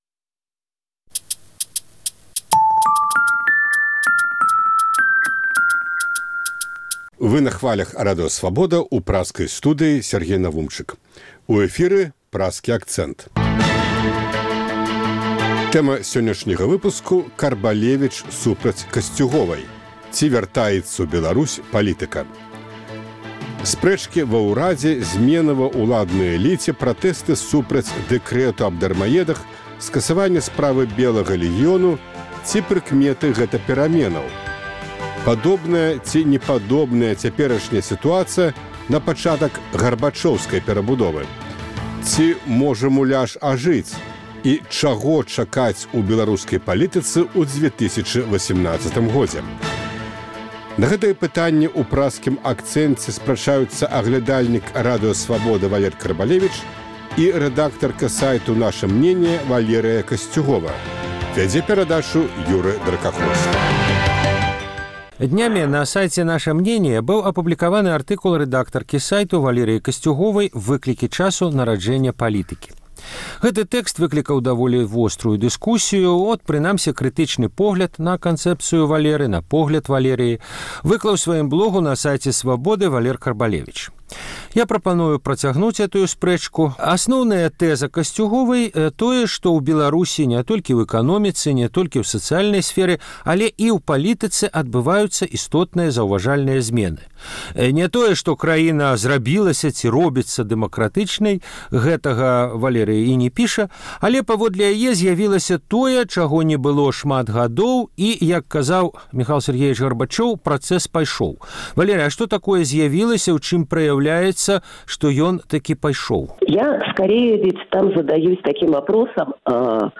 Штотыднёвы круглы стол экспэртаў і аналітыкаў на актуальную тэму.